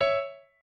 piano8_40.ogg